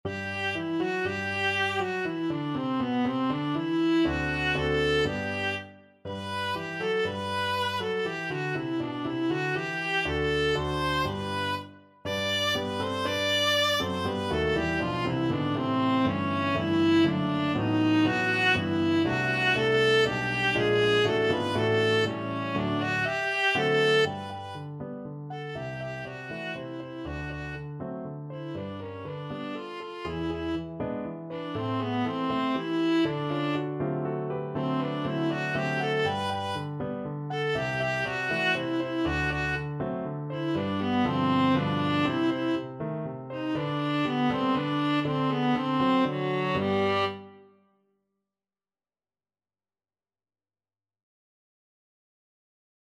Allegro (View more music marked Allegro)
3/4 (View more 3/4 Music)
Classical (View more Classical Viola Music)
Cuban